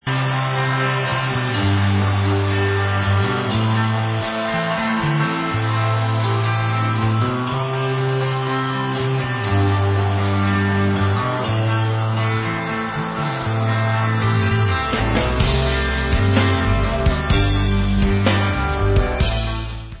Live At Fox Theatre
sledovat novinky v oddělení Southern (jižanský) rock